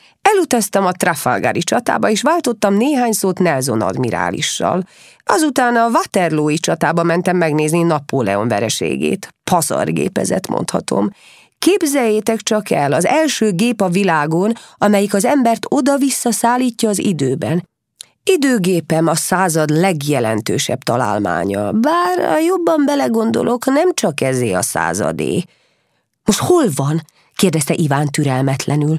Album: Hangoskönyvek gyerekeknek